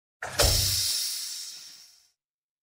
Among Us Doors Closing Sound Effect Free Download
Among Us Doors Closing